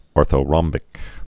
(ôrthō-rŏmbĭk)